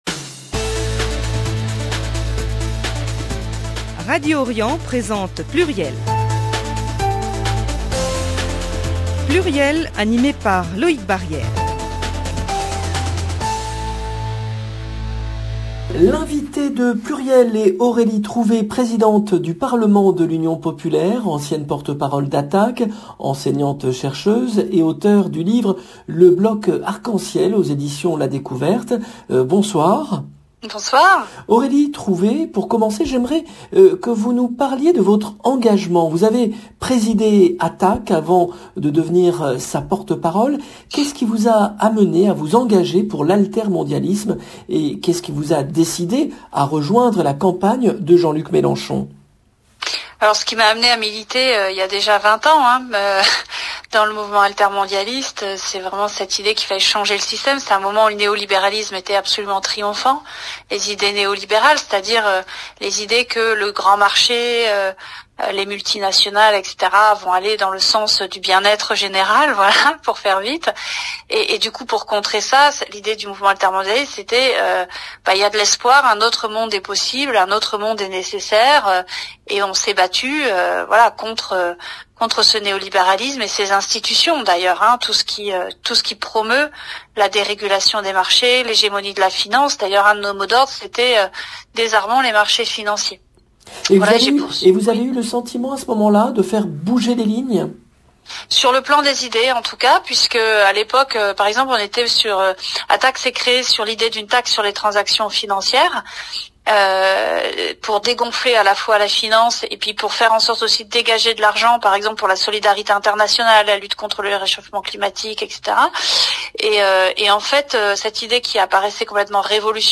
L’invitée de PLURIEL est Aurélie Trouvé, Présidente du parlement de l'Union populaire, ancienne porte-parole d'Attac, enseignante chercheuse et auteure du livre “Le Bloc Arc-en-ciel” aux éditions la Découverte